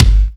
KICK39.wav